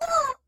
death1.ogg